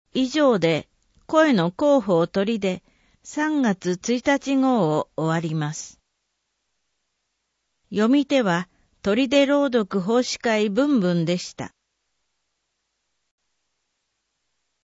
取手市の市報「広報とりで」2025年3月1日号の内容を音声で聞くことができます。音声データは市内のボランティア団体、取手朗読奉仕会「ぶんぶん」の皆さんのご協力により作成しています。